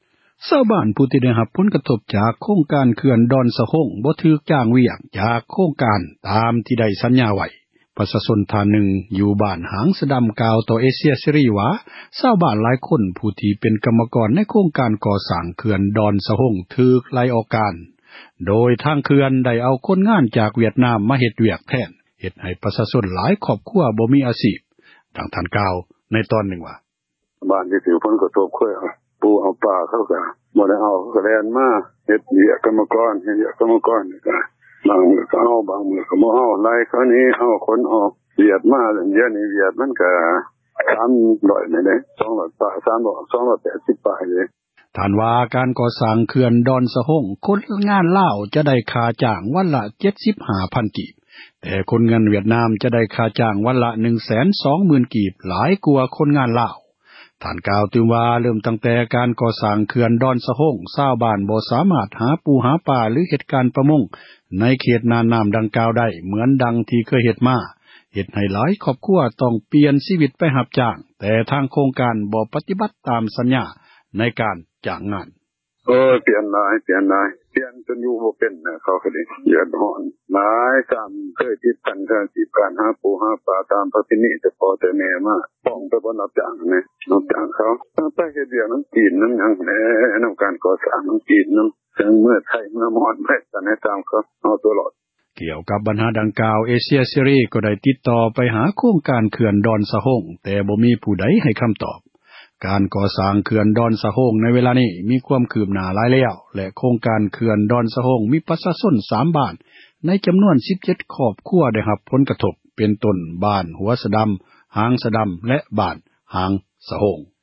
ປະຊາຊົນທ່ານນຶ່ງ ຢູ່ບ້ານຫາງສະດຳ ກ່າວຕໍ່ເອເຊັຽເສຣີວ່າ ຊາວບ້ານຫລາຍຄົນ ຜູ້ທີ່ເປັນ ກັມມະກອນ ໃນໂຄງການກໍ່ສ້າງ ເຂື່ອນ ດອນສະໂຮງ ຖືກໄລ່ອອກການ ຊຶ່ງໂຄງການ ເຂື່ອນ ນໍາເອົາຄົນງານ ຈາກວຽດນາມ ມາເຮັດວຽກແທນ ເຮັດໃຫ້ ປະຊາຊົນຫລາຍ ຄອບຄົວ ບໍ່ມີອາຊີບ. ດັ່ງທ່ານກ່າວ ໃນຕອນນື່ງວ່າ: